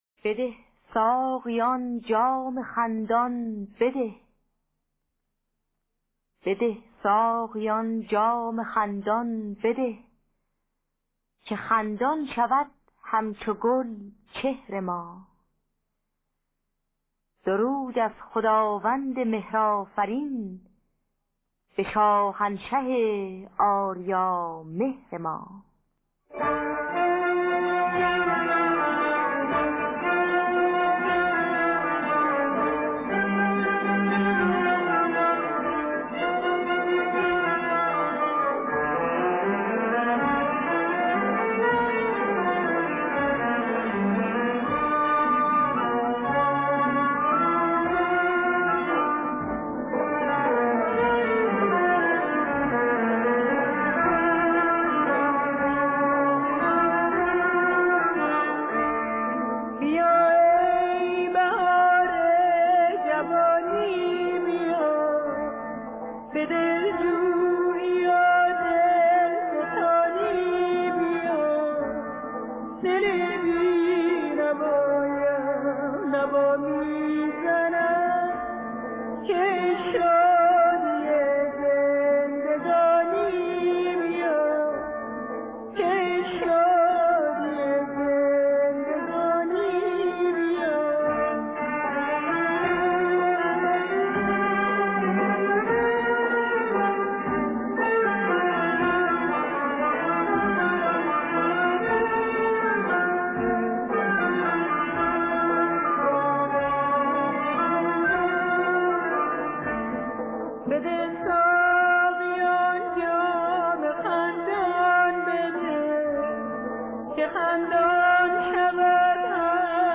دانلود گلهای رنگارنگ ۴۴۹ با صدای الهه، اکبر گلپایگانی در دستگاه بیات اصفهان.
خوانندگان: الهه اکبر گلپایگانی نوازندگان: همایون خرم فرهنگ شریف